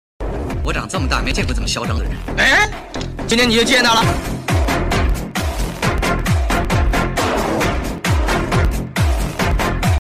The solo robot is walking#Unicycle